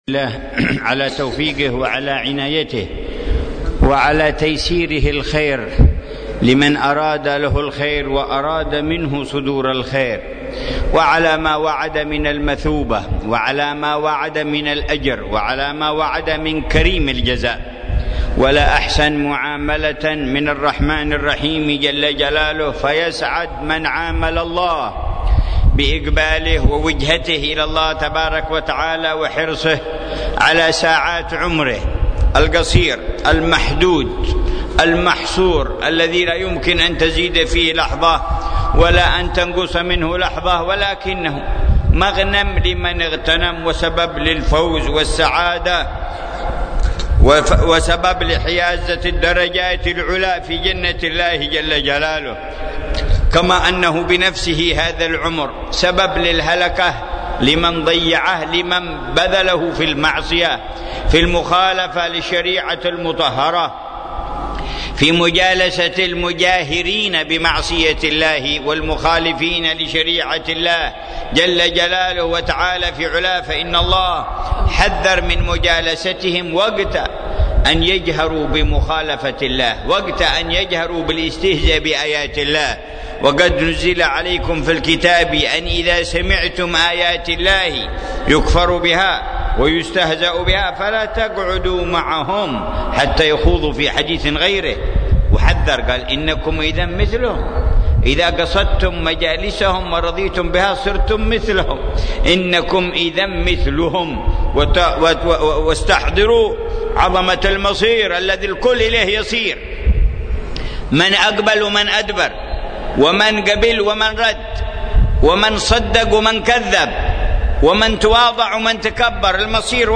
محاضرة
في جامع الشحر